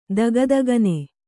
♪ daga dagane